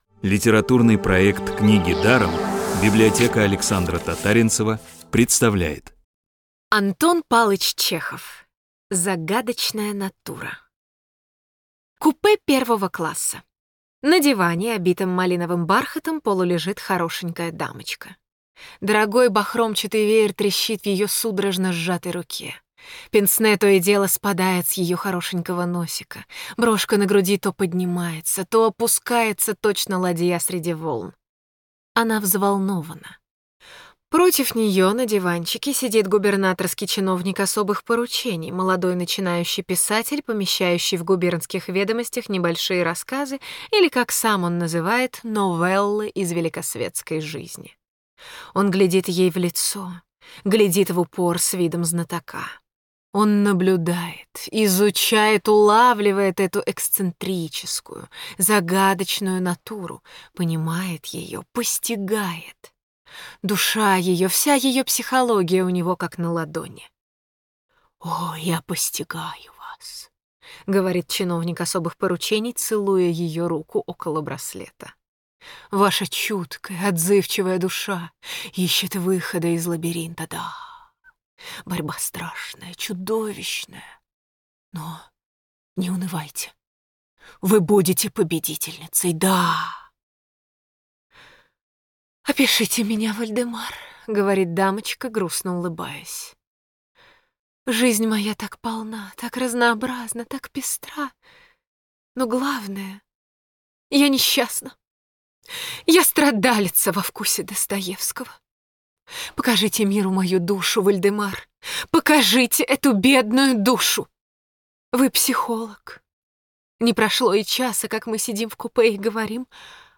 Главная Аудиокниги Для детей
Аудиокниги онлайн – слушайте «Загадочную натуру» в профессиональной озвучке и с качественным звуком.